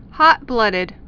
(hŏtblŭdĭd)